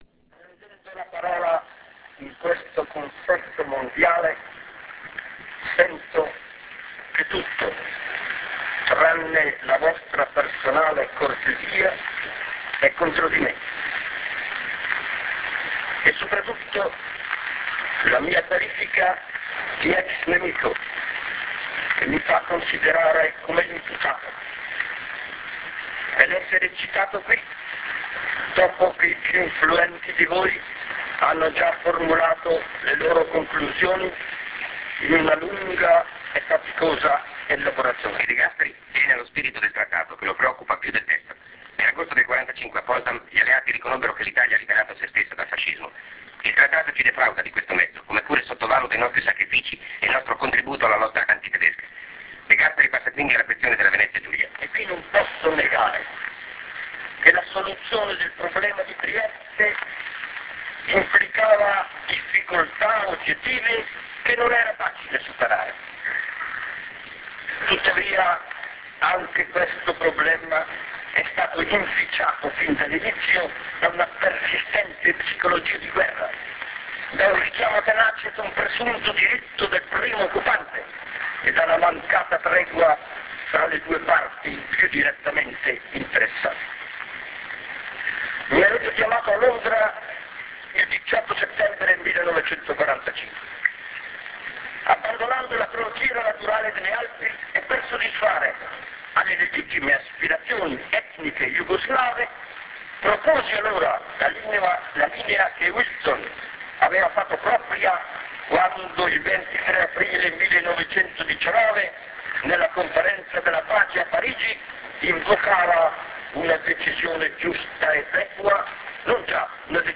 1946 De Gasperi alla Conferenza di Parigi (audio)